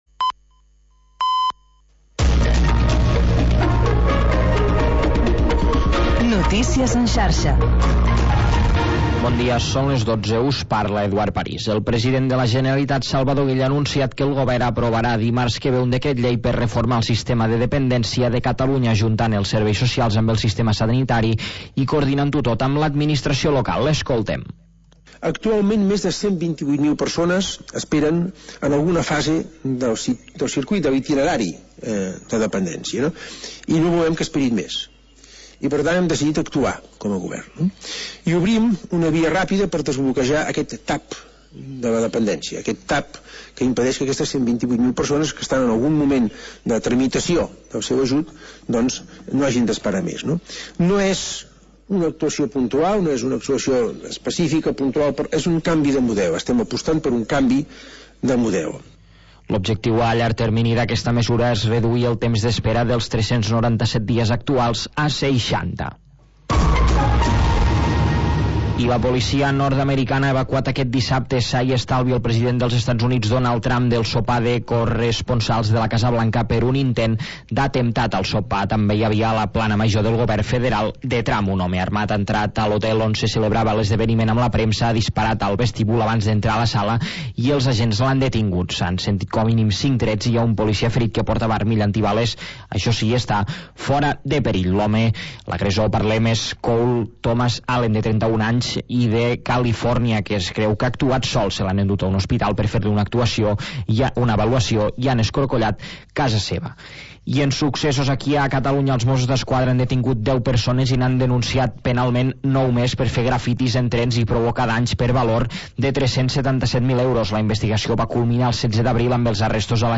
Música nostàlgica dels anys 50, 60 i 70